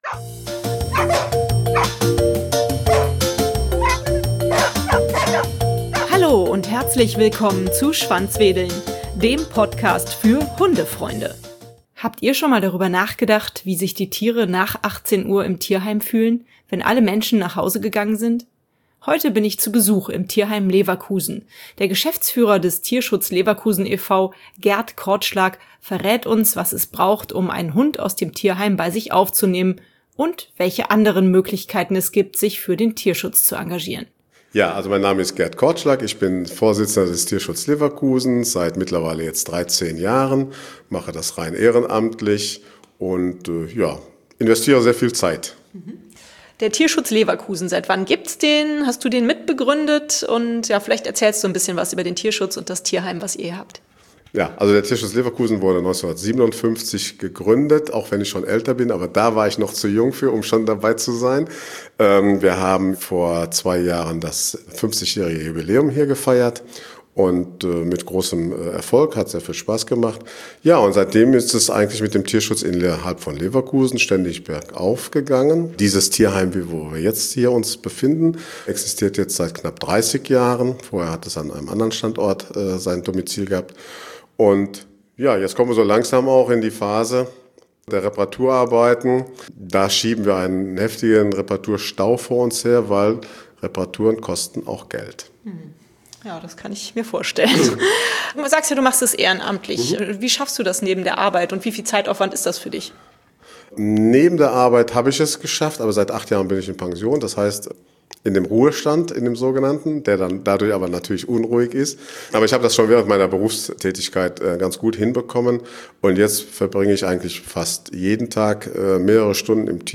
Beschreibung vor 6 Jahren Habt Ihr schon mal drüber nachgedacht, wie sich die Tiere nach 18 Uhr im Tierheim fühlen, wenn alle Menschen nach Hause gegangen sind? Heute bin ich zu Besuch im Tierheim Leverkusen.